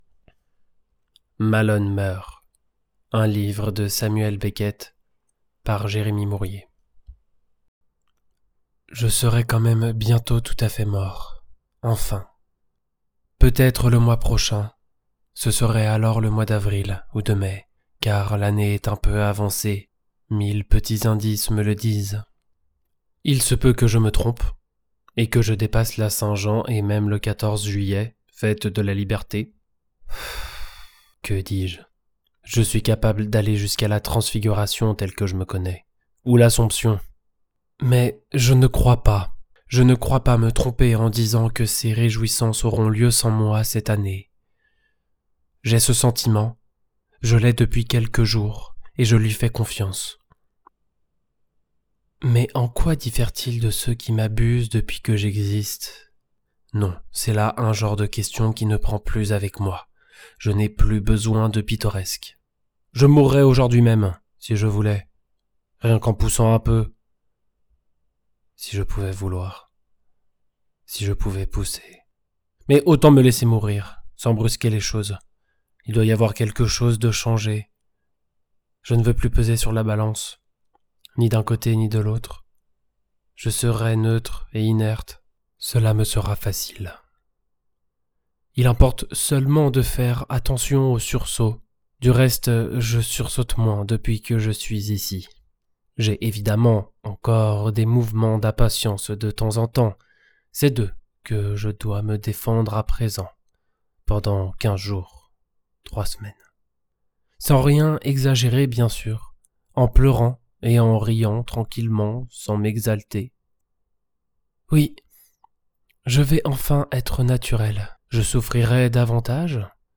Extrait livre audio - Malone meurt
Narrateur
- Baryton